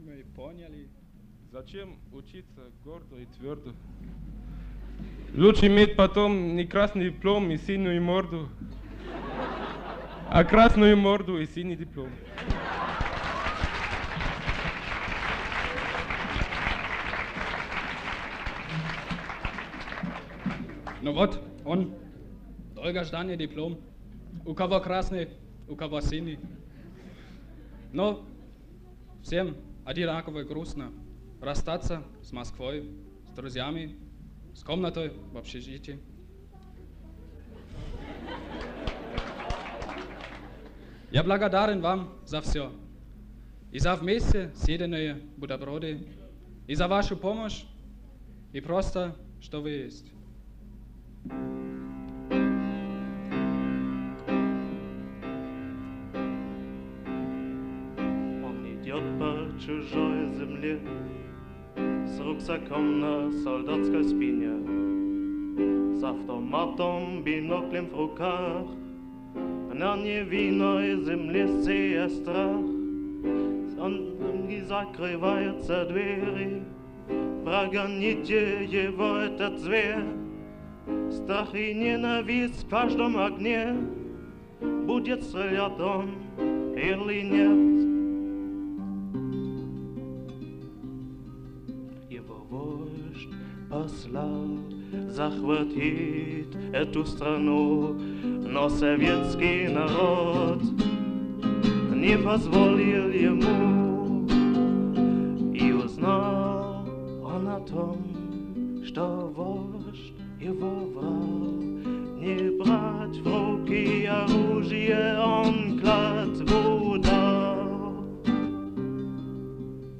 Rucksacklied1984 SUSI - SU Singe / Moskau / Rucksack-Programm 13Радуга/RadugaМЭИ1984